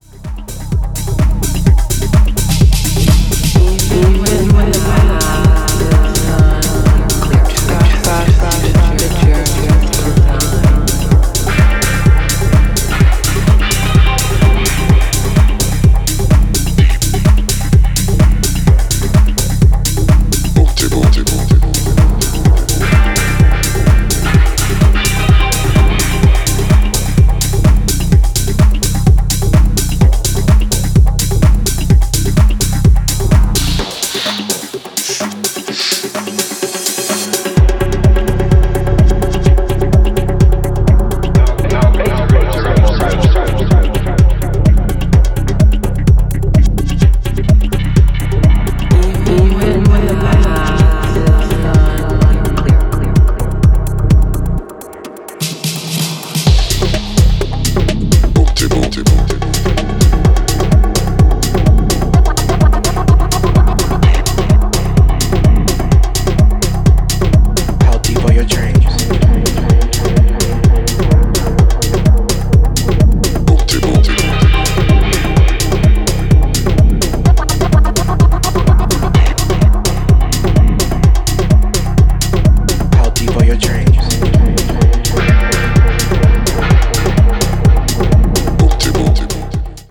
UKGからの影響も感じられるベースラインの圧の強さが新質感のモダン・ディープ・ハウスを展開